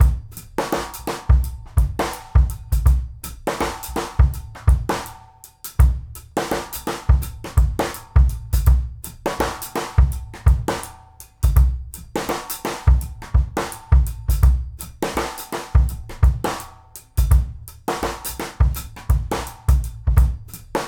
GROOVE 130CL.wav